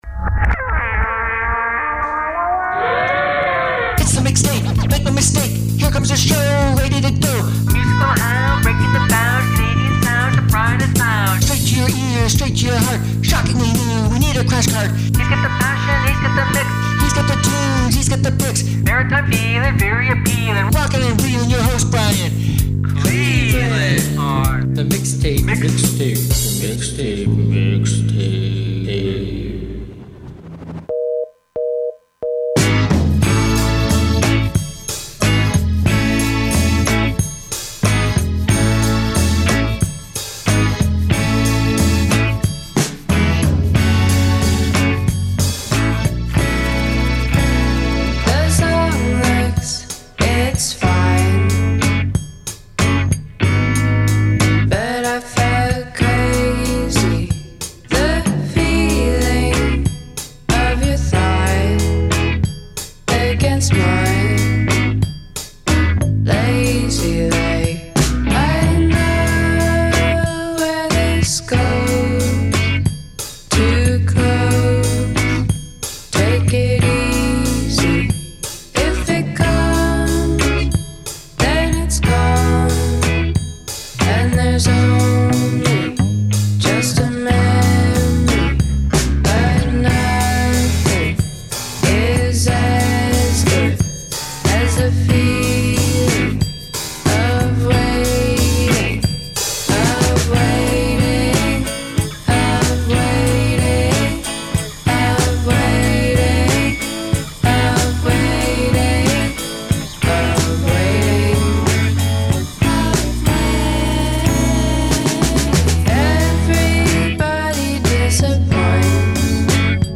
S13E31: Weekly all-Canadian community radio music potpourri